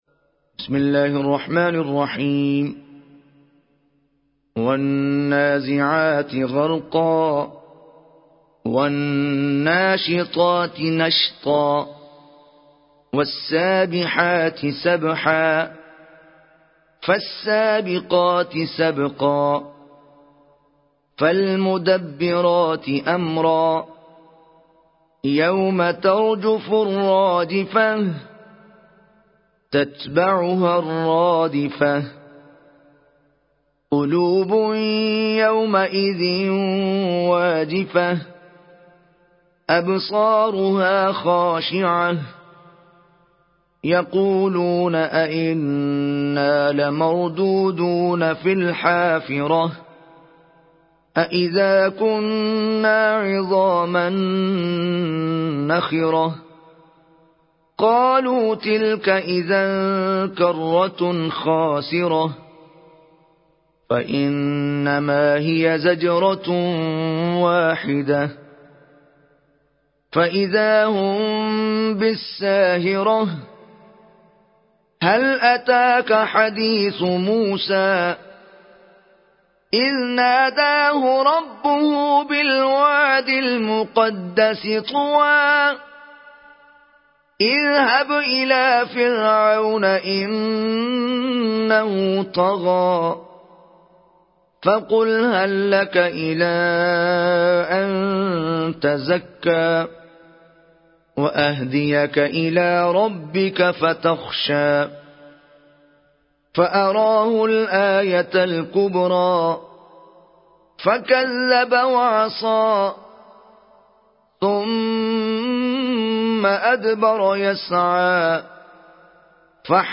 Complete Quran Recitation arabic Surah Naziat Download